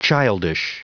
Prononciation du mot childish en anglais (fichier audio)
Prononciation du mot : childish